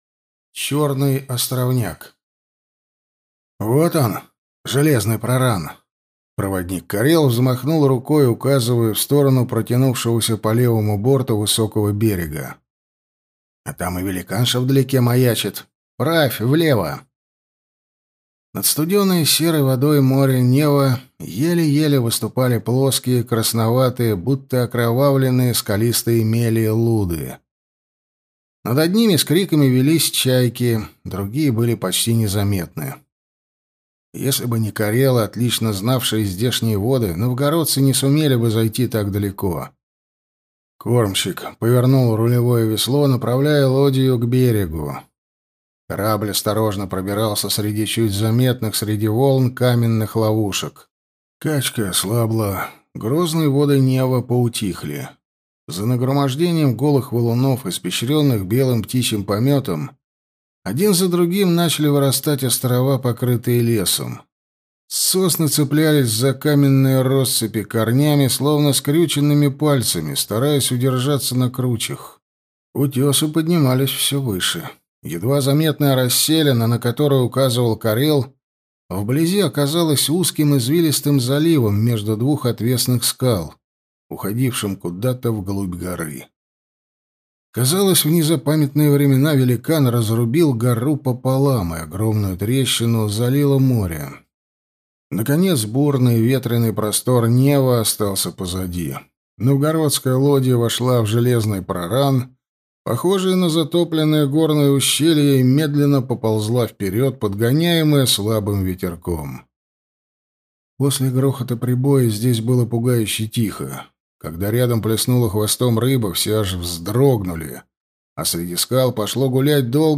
Аудиокнига Колдун со Змеева моря | Библиотека аудиокниг